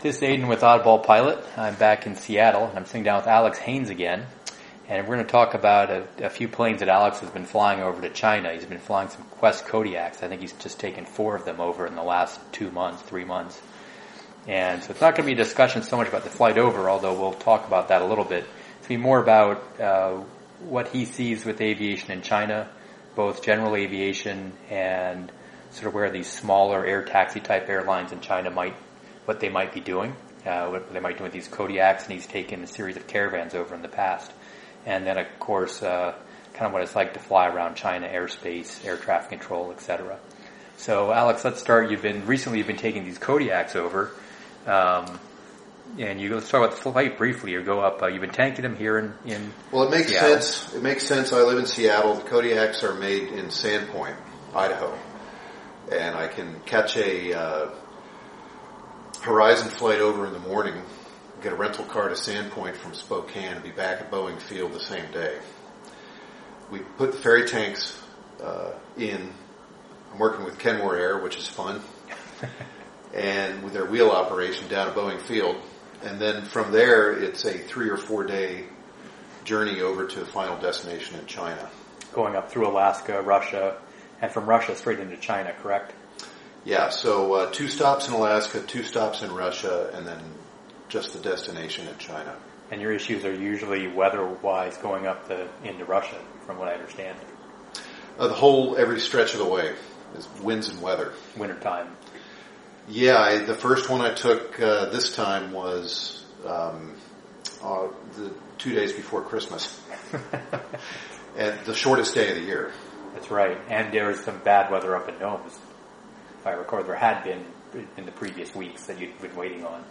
aviation_in_china_discussion.mp3